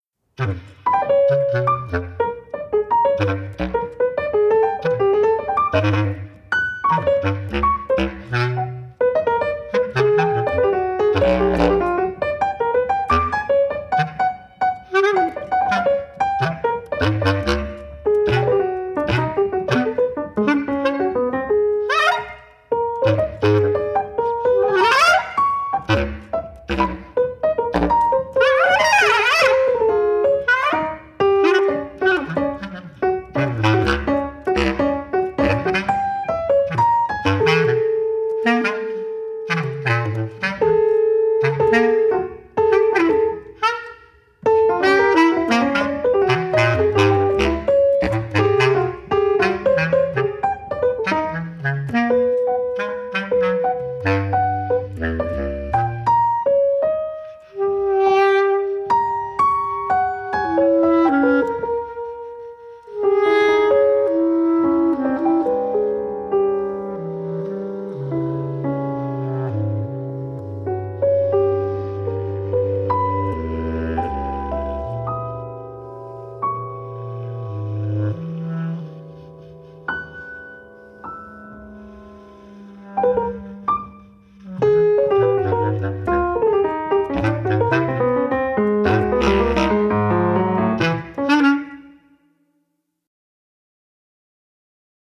bass clarinet
piano